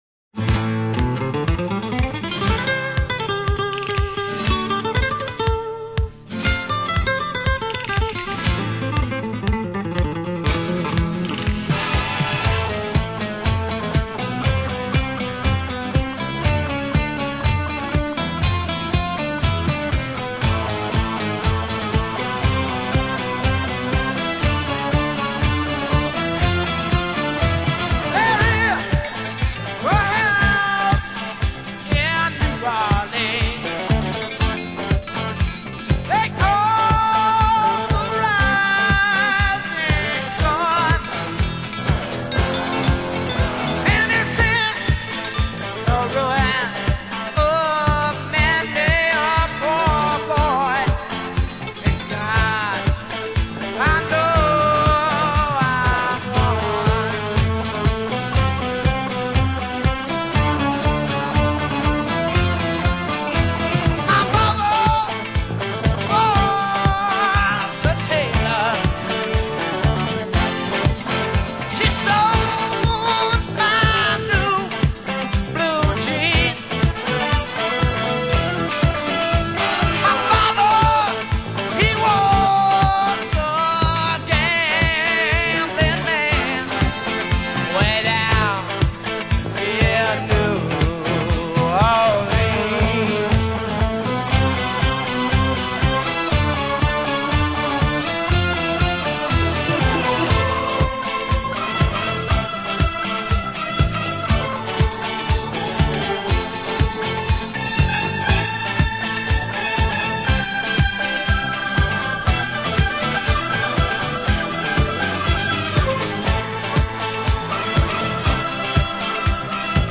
Genre: Pop;Disco